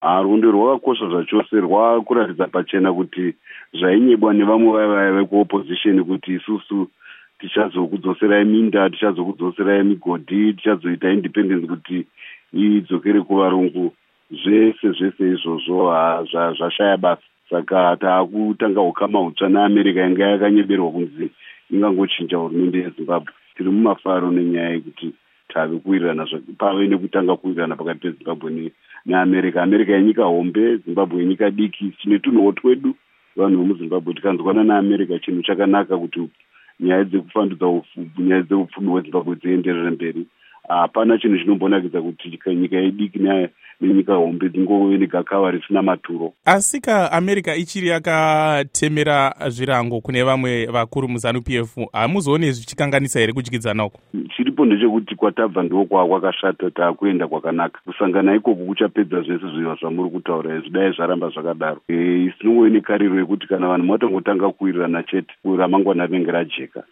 Hurukuro naVaChris Mutsvangwa